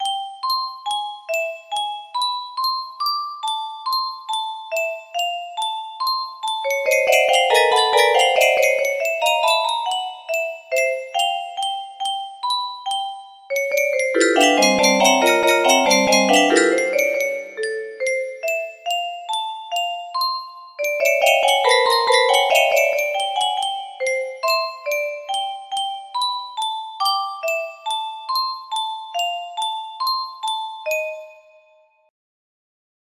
Unknown Artist - Untitled music box melody
Grand Illusions 30 (F scale)